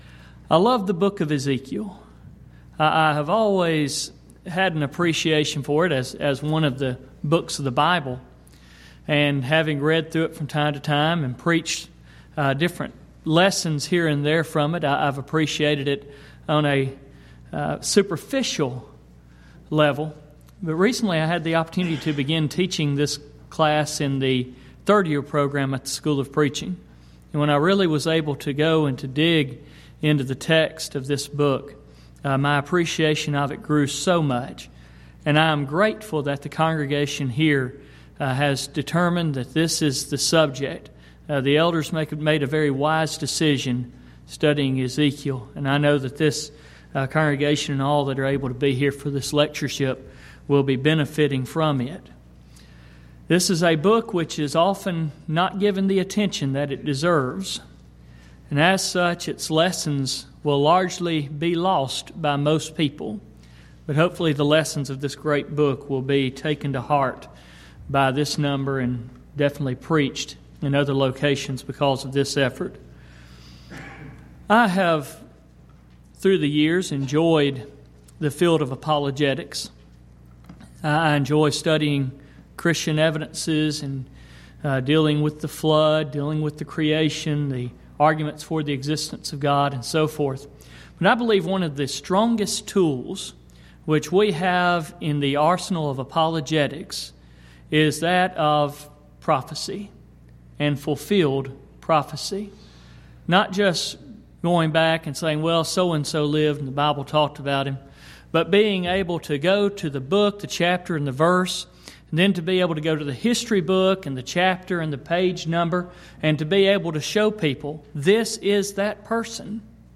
Event: 10th Annual Schertz Lectures
lecture